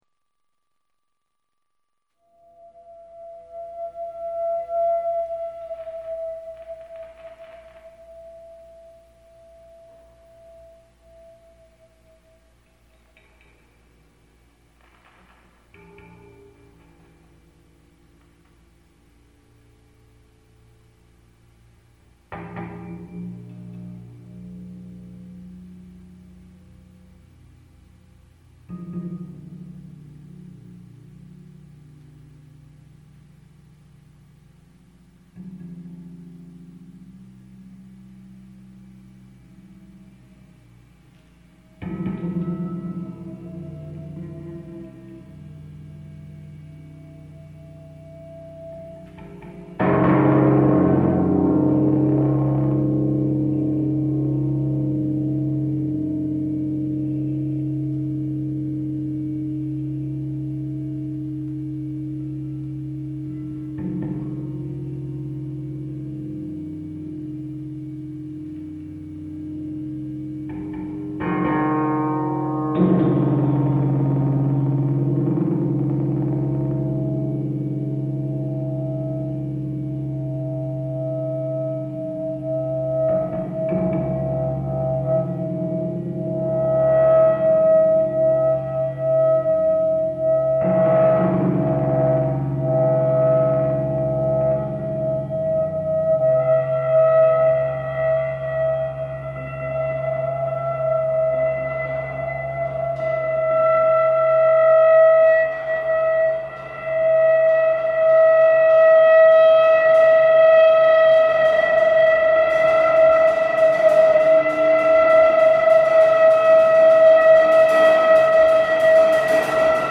experimental percussion & noise duo   web page
guitar
Percussion and feedback.